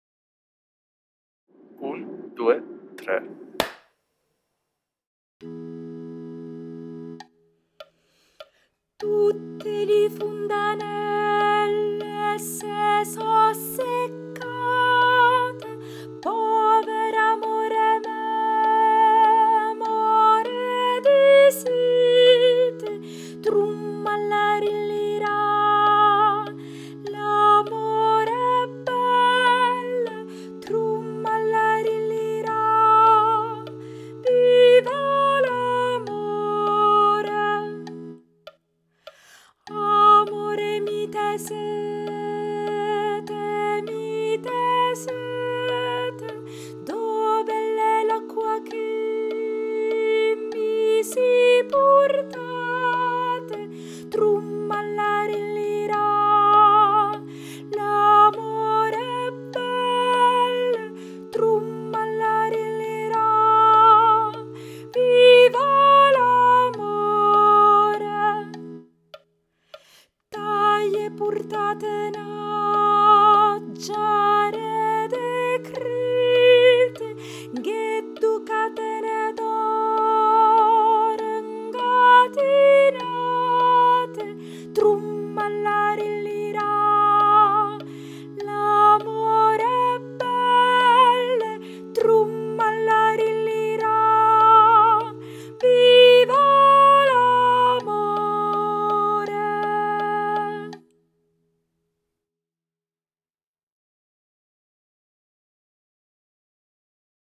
🎧 Voce guida
Soprani